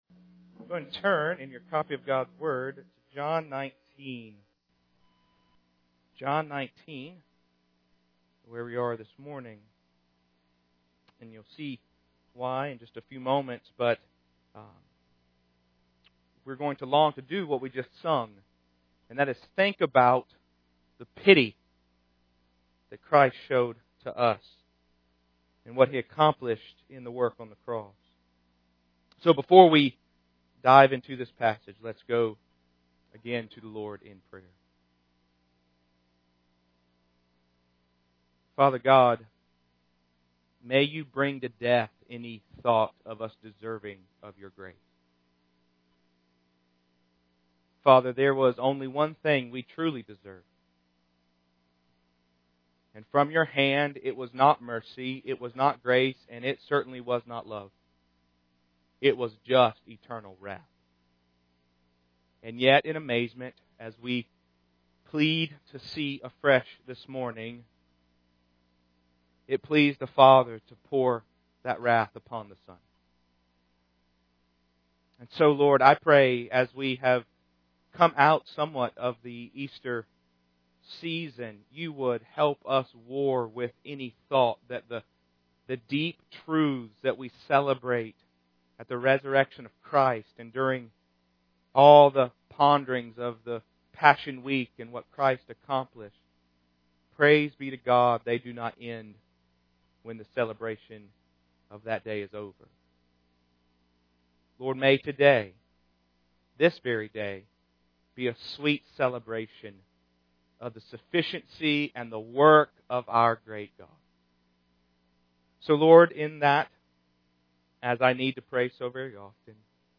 The artwork below was shared as part of the sermon: